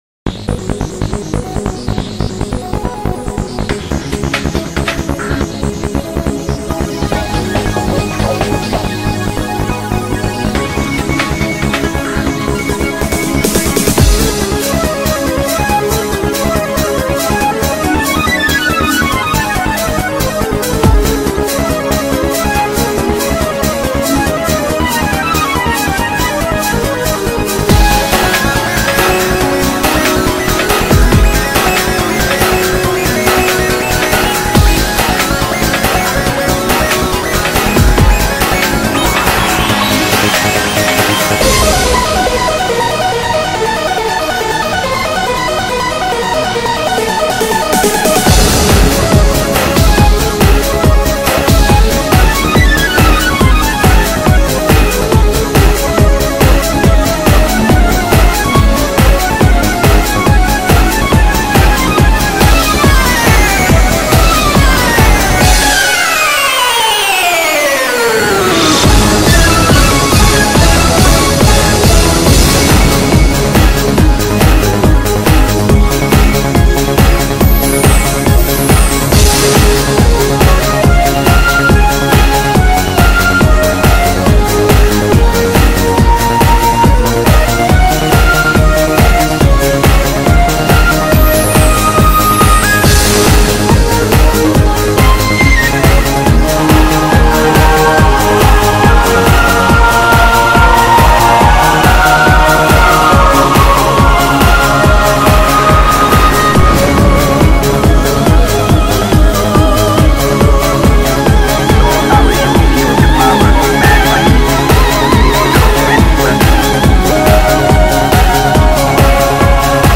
BPM140-140
Audio QualityPerfect (Low Quality)